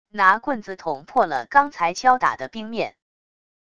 拿棍子捅破了刚才敲打的冰面wav音频